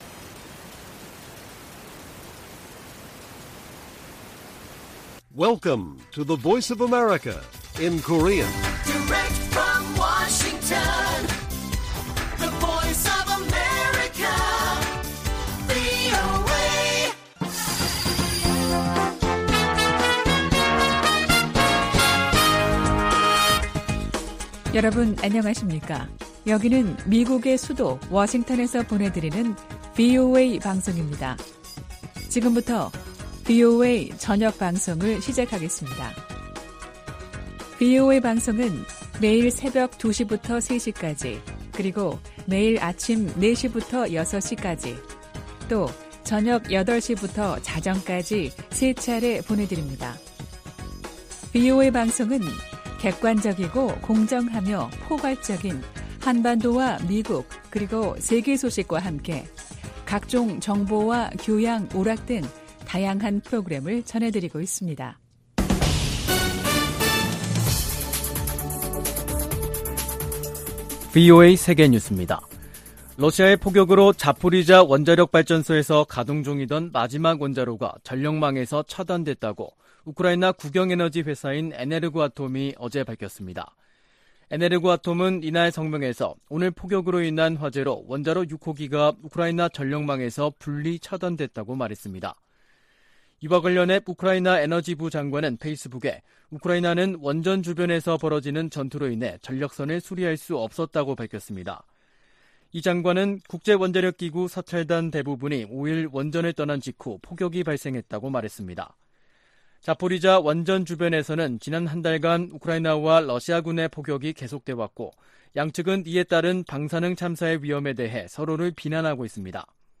VOA 한국어 간판 뉴스 프로그램 '뉴스 투데이', 2022년 9월 6일 1부 방송입니다. 미국과 한국, 일본 외교수장들은 북한이 7차 핵실험을 감행할 경우 이전과는 다른 대응을 예고했습니다. 미한일 협력이 강조되는 가운데, 한일관계에는 여전히 온도차가 있다고 미국 전문가들이 지적했습니다. 봄 가뭄과 ‘코로나’ 이중고를 겪고 있는 북한 경제에 “개혁・개방이 살 길”이라고 미국 전문가들이 진단했습니다.